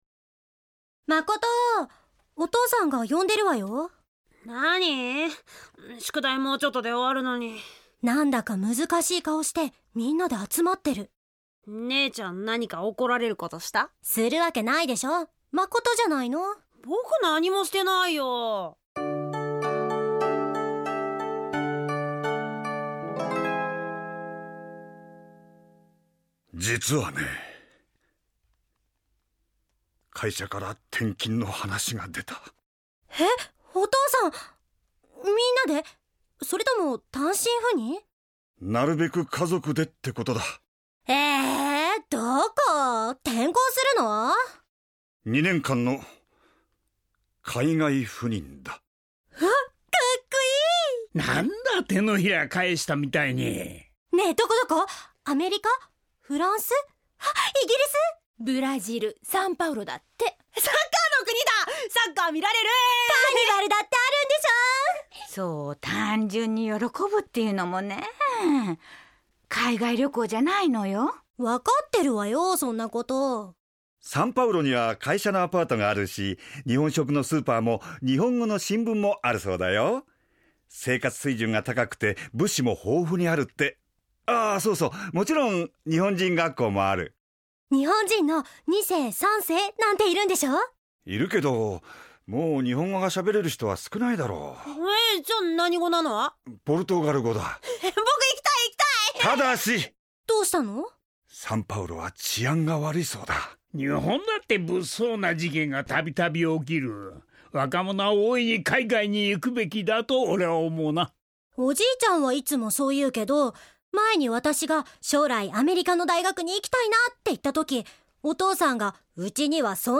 ●ラジオドラマ「鈴木家の教訓」